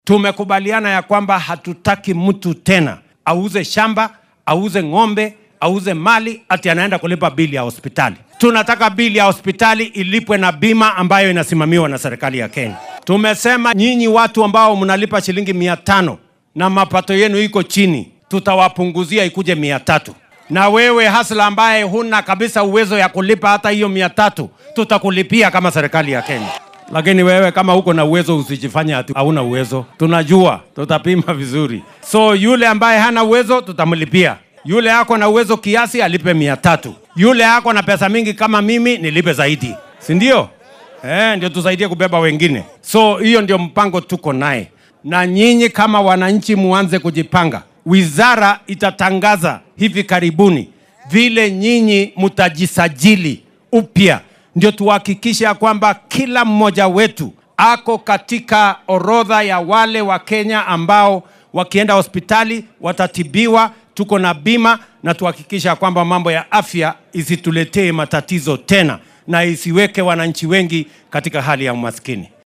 DHAGEYSO:Madaxweynaha dalka oo ka hadlay caymiska caafimaad ee SHIF
Madaxweynaha dalka William Ruto ayaa sheegay in kenyaanka hadda isticmaala caymiska qaran ee caafimaadka ee NHIF mar kale la diiwaangelin doono si ay uga faa’iidaystaan nidaamka cusub ee SHIF. Arrintan ayuu shaaca ka qaaday xilli uu ku sugnaa ismaamulka Bomet.